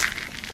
default_gravel_footstep.2.ogg